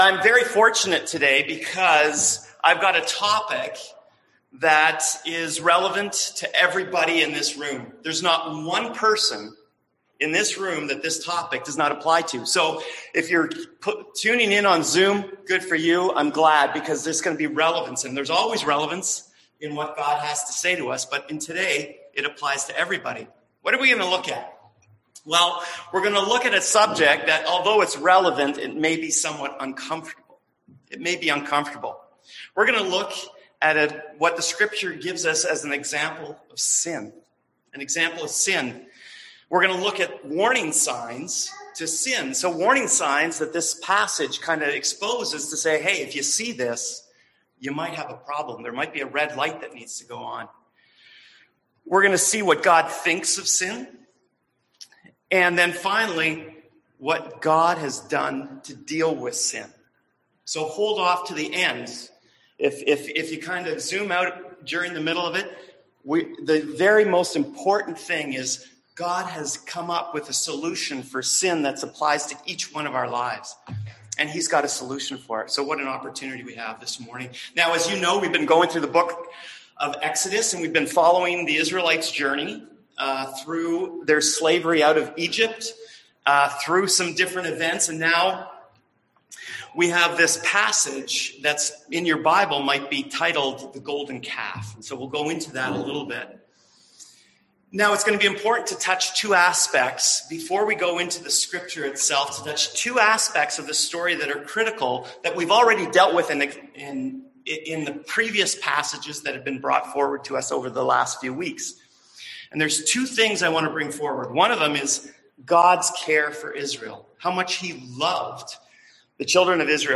Service Type: Sunday AM Topics: Idolatry , Sin , Temptation